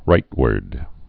(rītwərd)